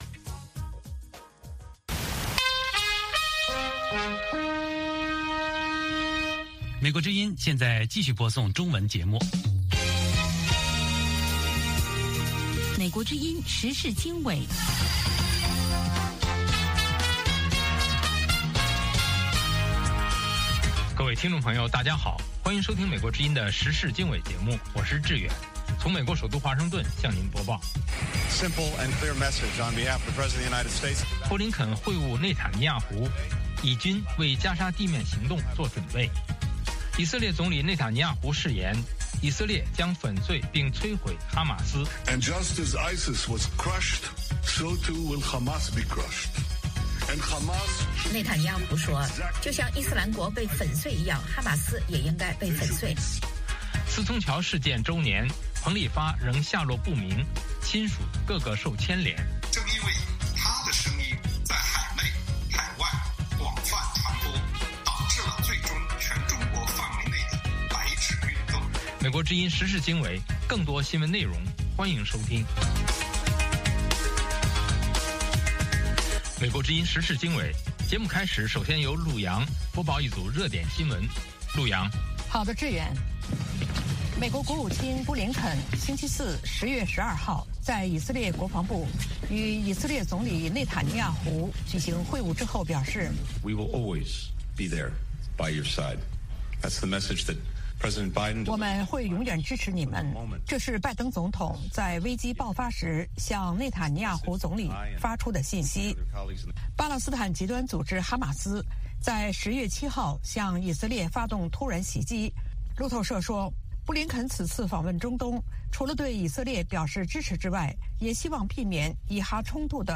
美国之音英语教学节目。